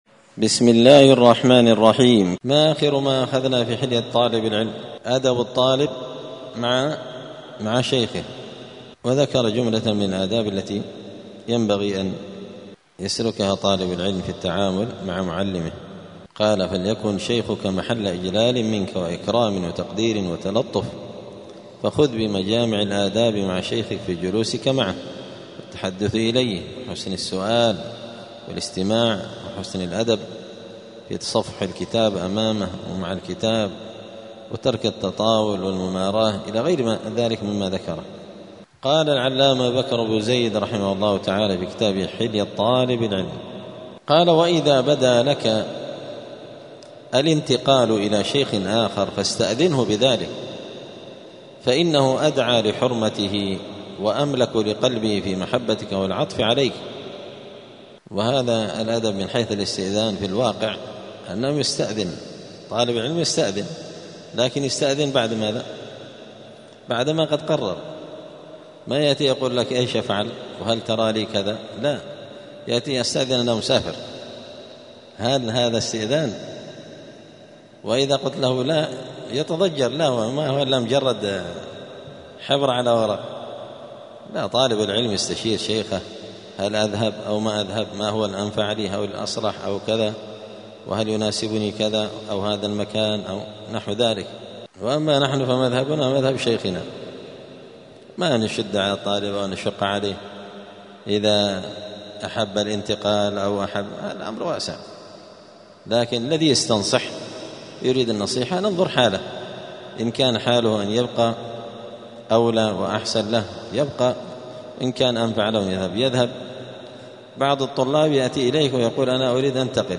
*الدرس الثالث والثلاثون (33) {فصل أدب الطالب مع شيخه استأذن شيخك في الانتقال إلى شيخ آخر}*
دار الحديث السلفية بمسجد الفرقان قشن المهرة اليمن
33الدرس-الثالث-والثلاثون-من-كتاب-حلية-طالب-العلم.mp3